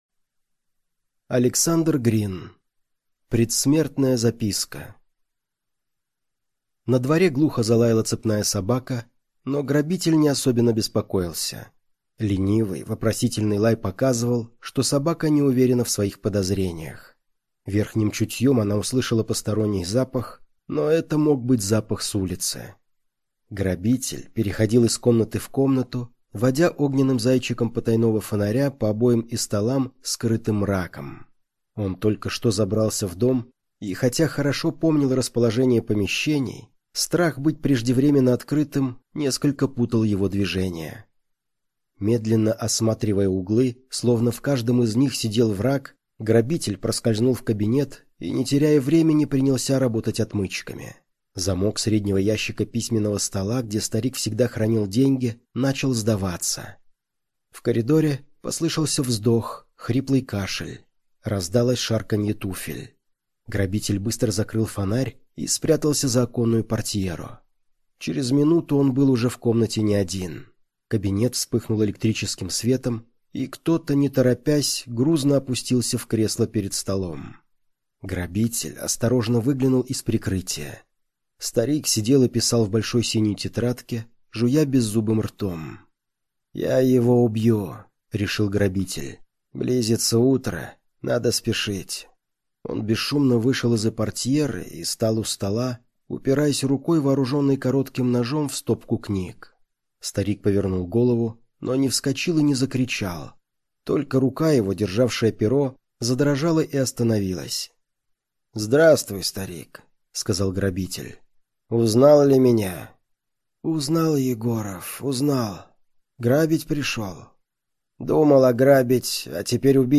На данной странице вы можете слушать онлайн бесплатно и скачать аудиокнигу "Предсмертная записка" писателя Александр Грин.